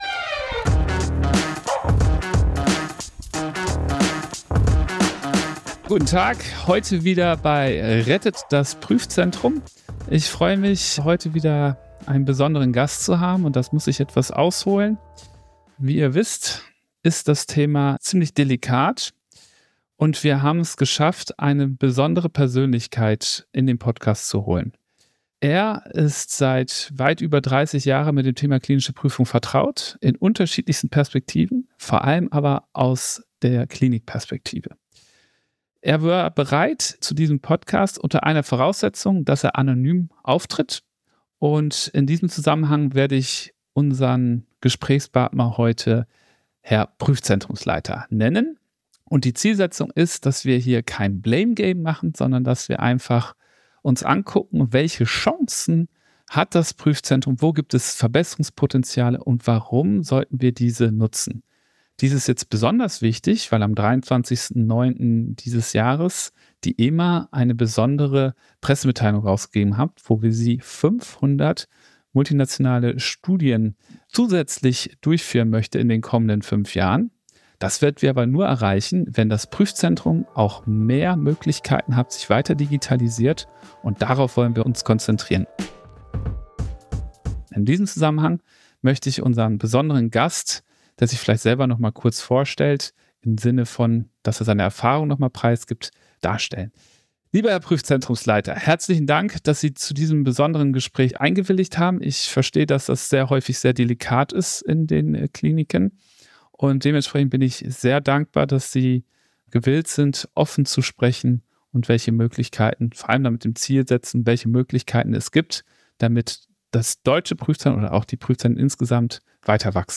Freuen Sie sich auf einen kritischen, tiefgehenden und außergewöhnlich ehrlichen Austausch.